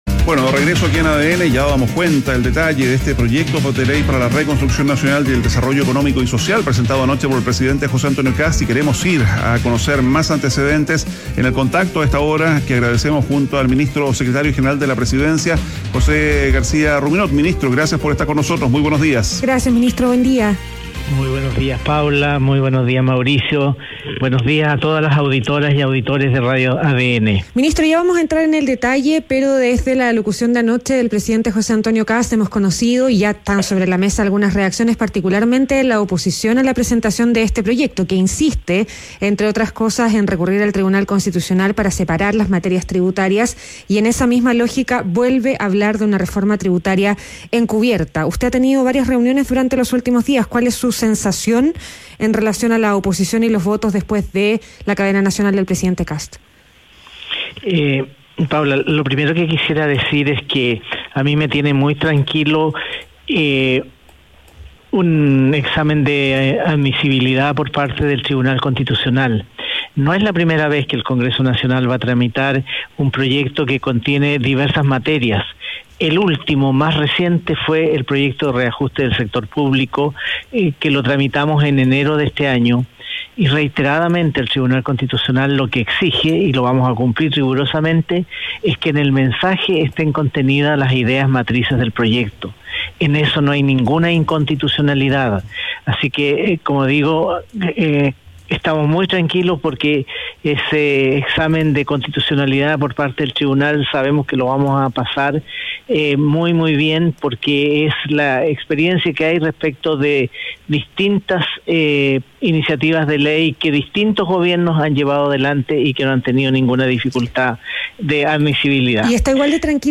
En conversación con ADN Hoy, el ministro de la Segpres, José García Ruminot, salió a responder a la oposición y a las dudas por el contenido del proyecto, asegurando que la iniciativa busca reactivar la economía, impulsar la inversión y frenar el desempleo.